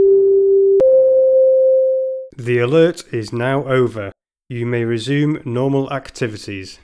Commercial Audio are able to design and supply audio system components which can replace or work alongside your existing communication systems to provide site wide initiation of a lockdown in response to a threat as well as voice announcements to confirm the status of the emergency to all occupants.
All Clear Message
ALL CLEAR.wav